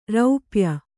♪ raupya